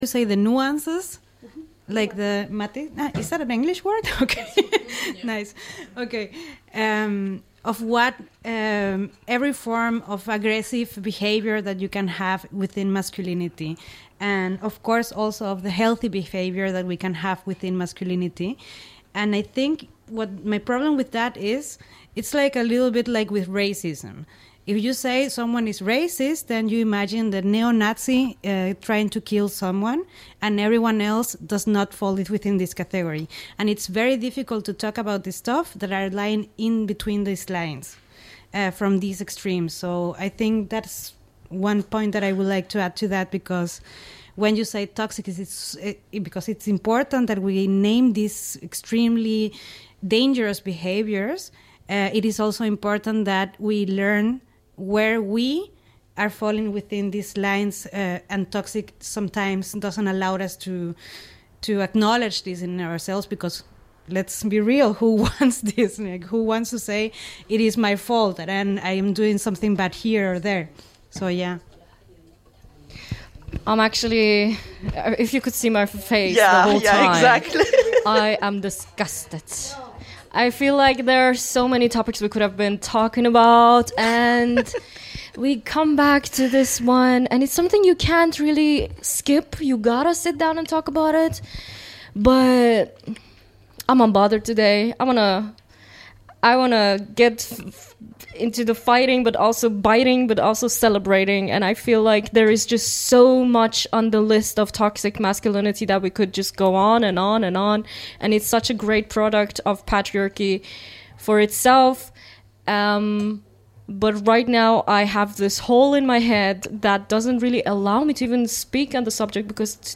Es ist eine Sendung von Gefl�chteten und MigrantInnen in Halle und Umgebung.